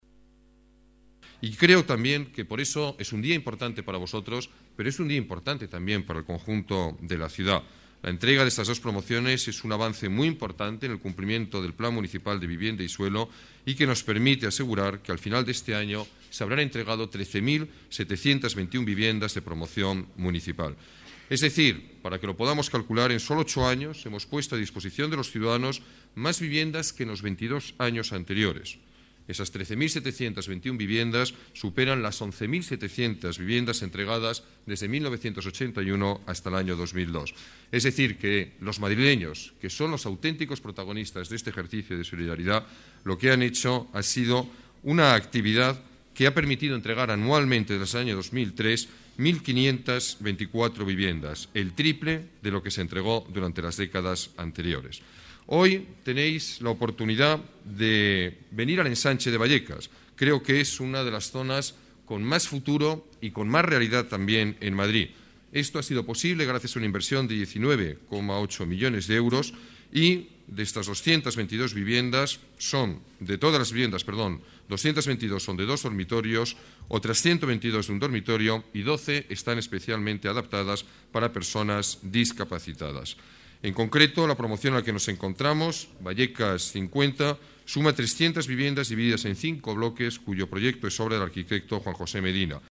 Más archivos multimedia Palabras del alcalde, Alberto Ruiz-Gallardón Más documentos Datos sobre viviendas municipales de alquiler Balance de la política municipal de vivienda Promoción Vallecas (1) Promoción Vallecas (1)